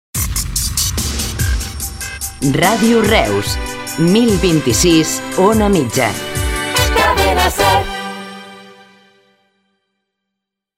Identificació i freqüència de l'emissora